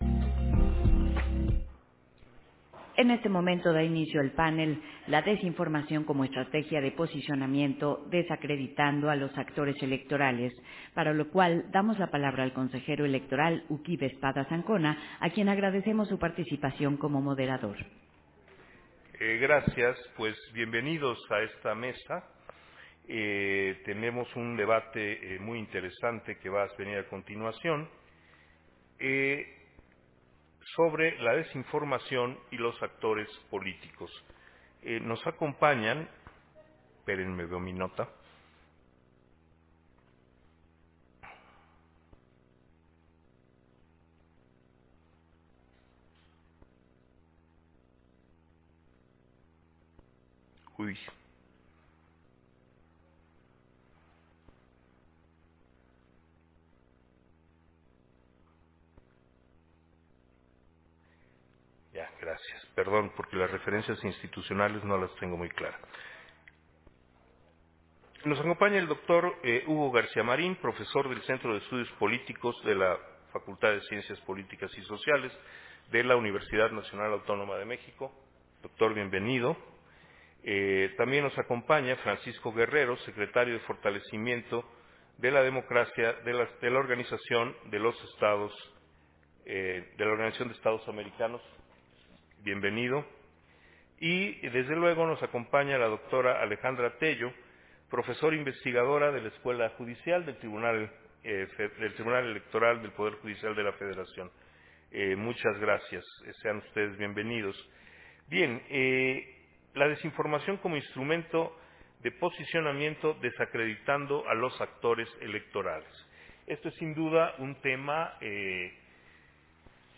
051223_AUDIO_PANEL-LA-DESINFORMACIÓN-COMO-ESTRATEGIA-DE-POSICIONAMIENTO
Versión estenográfica del panel, La desinformación como estrategia de posicionamiento: Desacreditando a los actores electorales, en el marco de la II Cumbre de la Democracia Electoral